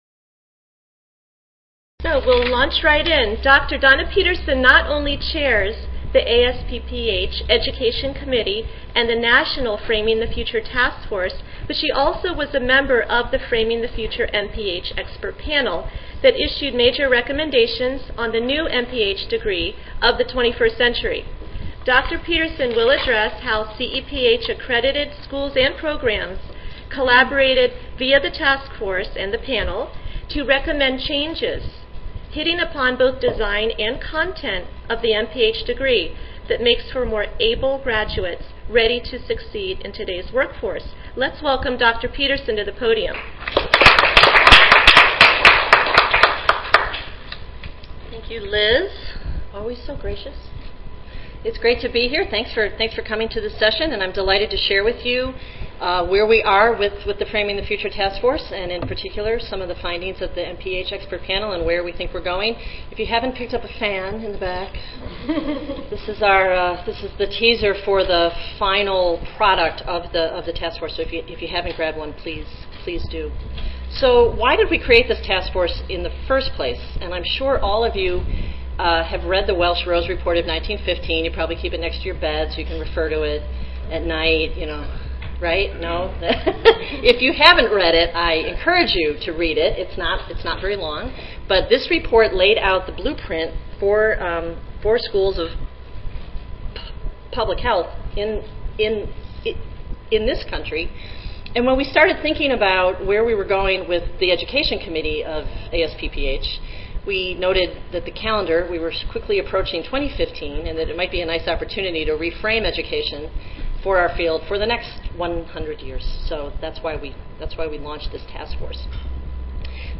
In January 2014, the panel released the A Master of Public Health Degree for the 21st Centuryreport that aims to address the needs of graduates and employers for modern public health practice. The speaker will present on the four major changes recommended for the MPH degree in th